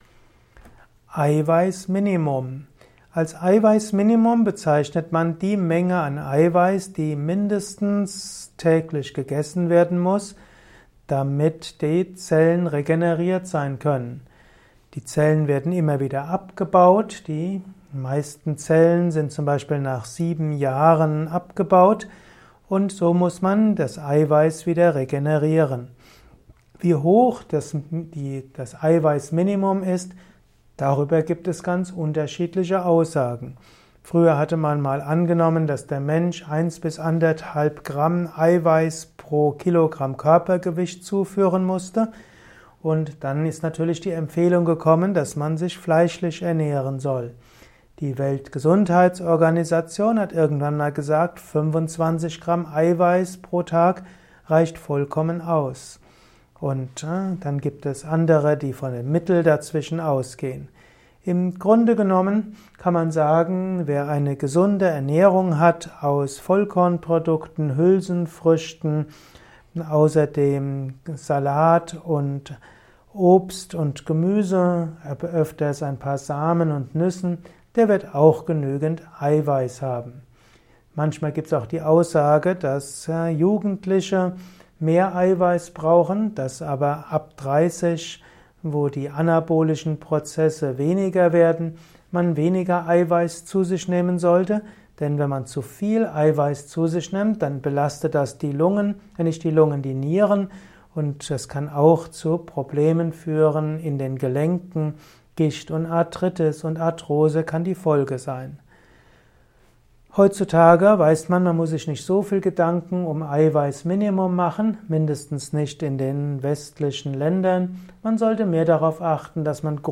Ein Kurzvortrag zum Thema Eiweißminimum